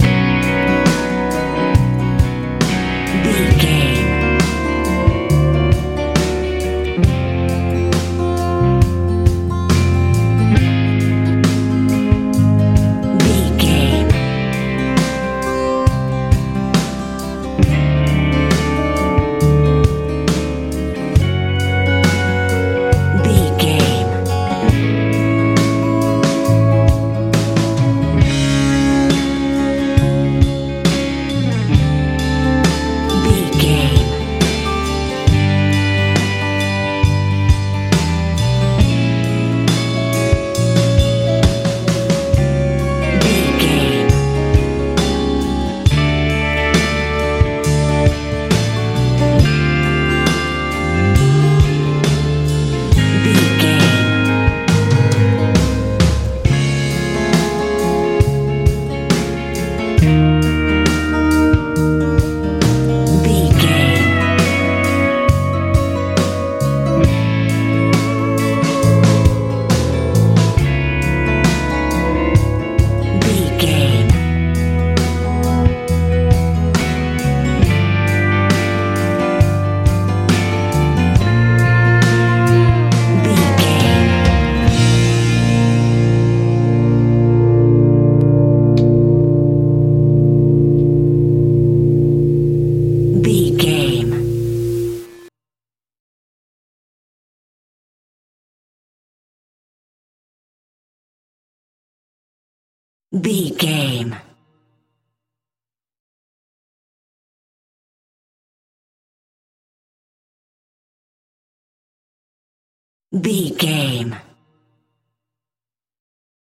rock ballad feel
Ionian/Major
F♯
light
electric guitar
bass guitar
drums
80s